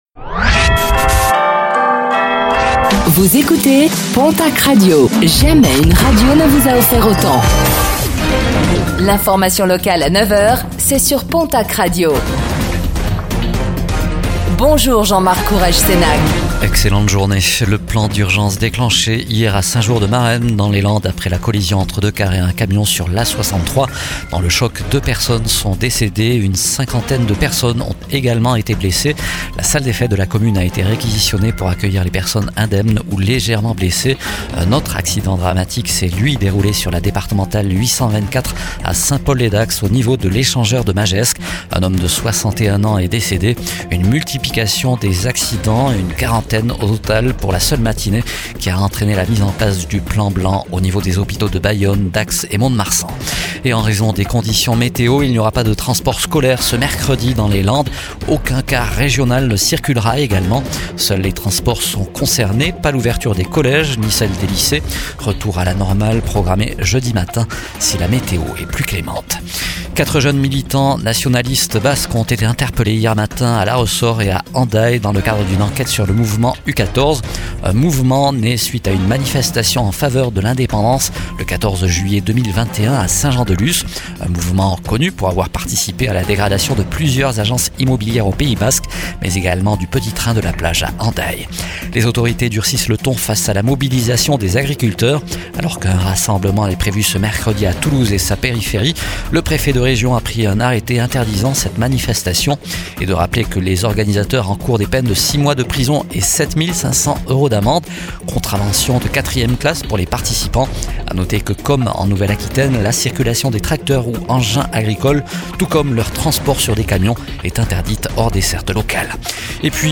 Réécoutez le flash d'information locale de ce mercredi 07 janvier 2026, présenté par